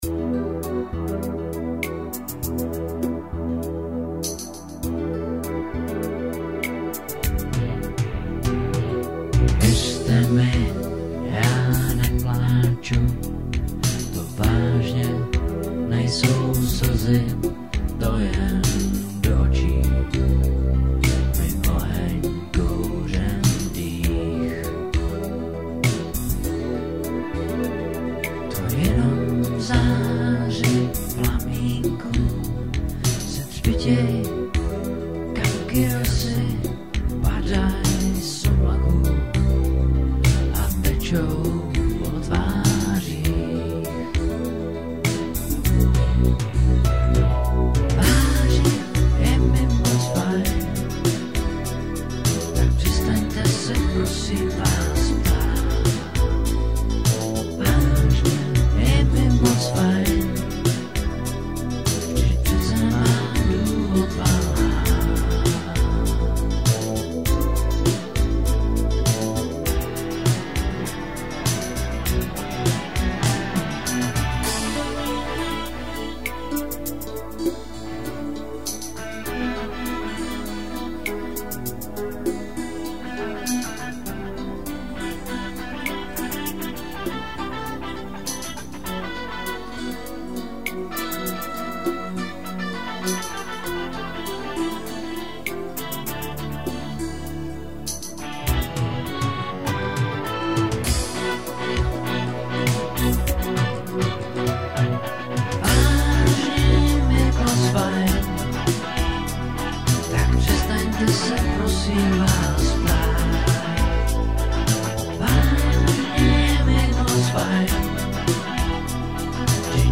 I když se obávám, že kdybych začal hodnotit... přijde mi dnes jako kuriozně ujeté skoro všechno :D Uvedená DEMO ukázka písně je o slzách... napsána byla někdy v r. 1988- 89 (?) ale nahrána do dema mnohem později. Je tu jako kuriozitka proto, že když jsem jí doma nahrával, nebyla zrovna k sehnání žádná zpěvačka... tak jsem se rozhodl, že to dám sám.... vřdyť je to demo jenom.